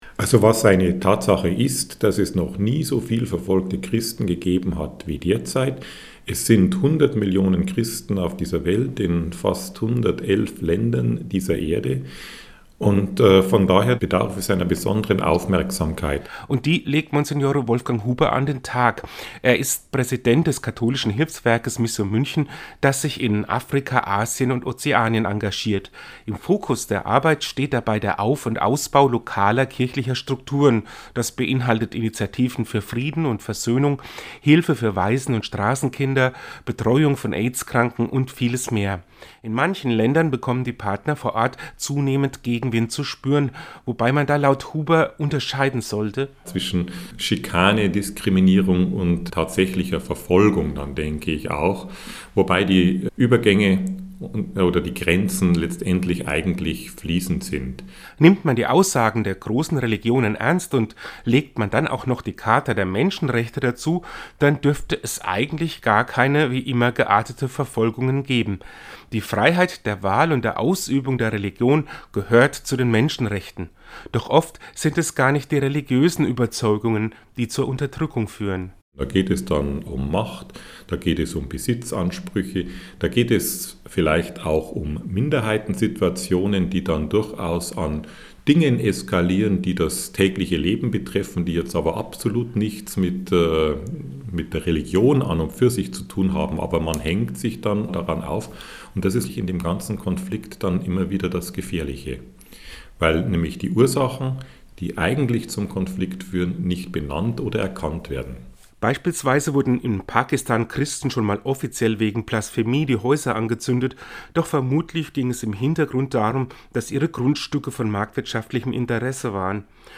Den Radiobericht finden Sie hier zum Download.